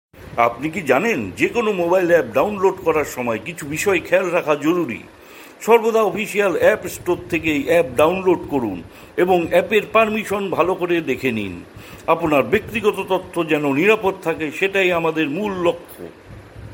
Explainer Video – Bengali (Mobile Security)